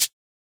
Closed Hats
edm-hihat-53.wav